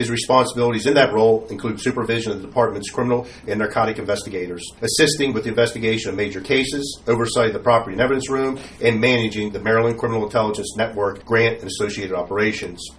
During Tuesday’s Cumberland City Council meeting, police chief Jim Pyles recognized the promotion of two officers to the office of Lieutenant.